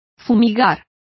Complete with pronunciation of the translation of fumigate.